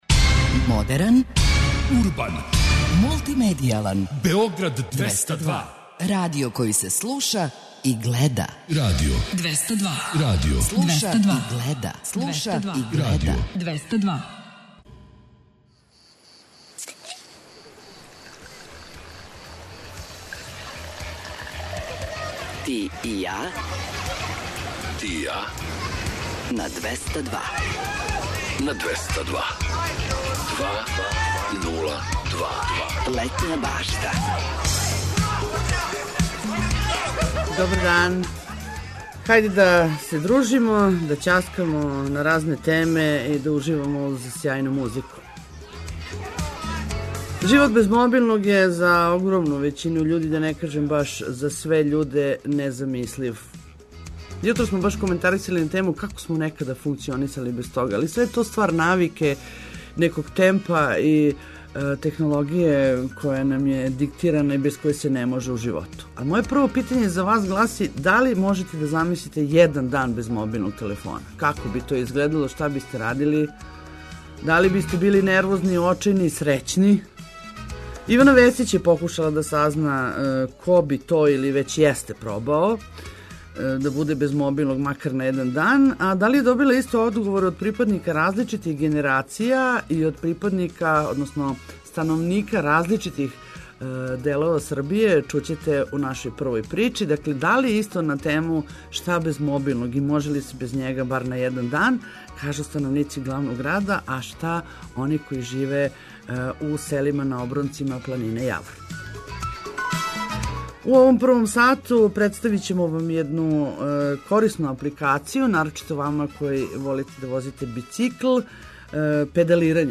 До 13 часова очекујте још и лепе вести, сервисне информације, културни водич и добру музику